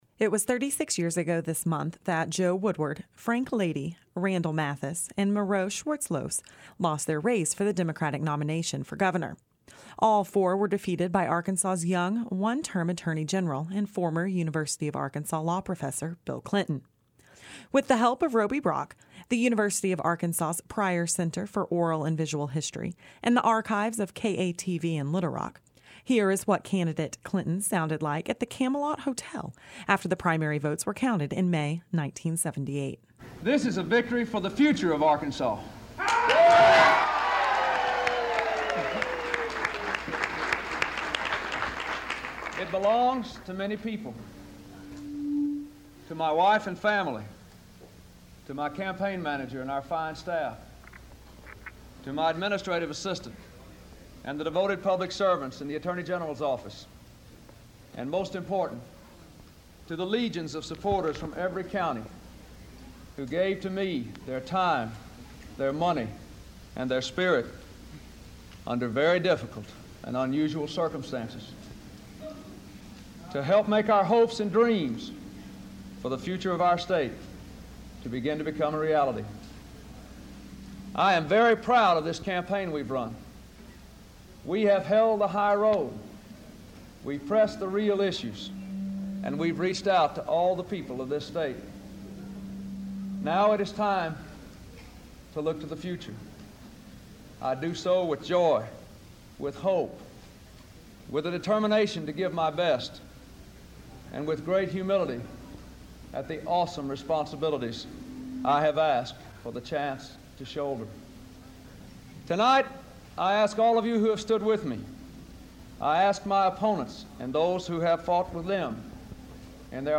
Archive Provides Sounds of 1978 Election
Here is what Bill Clinton sounded like at the Camelot Hotel after the primary votes were counted in May 1978.